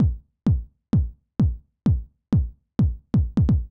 INT Beat - Mix 13.wav